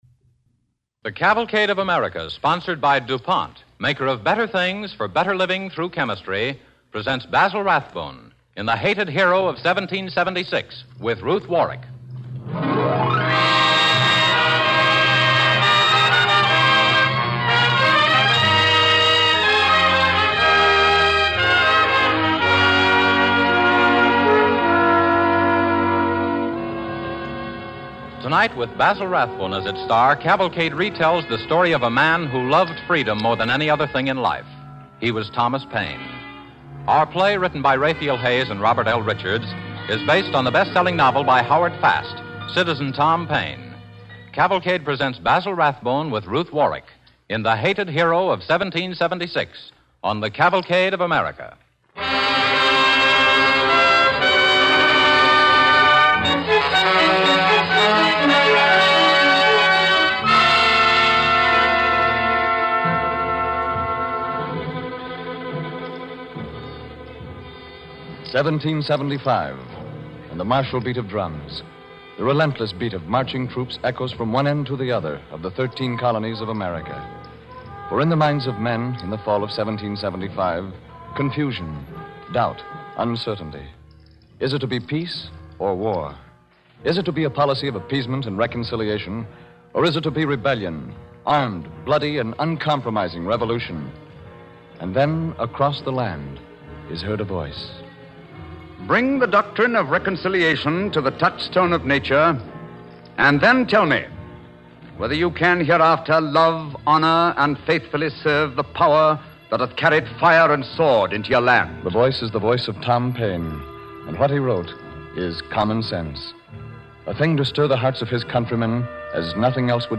The Hated Hero of 1776, starring Basil Rathbone and Ruth Warrick